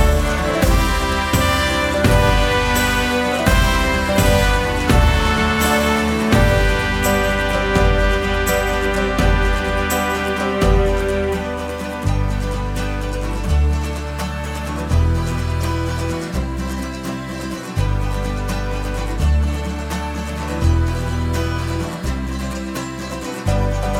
No Backing Vocals Easy Listening 3:37 Buy £1.50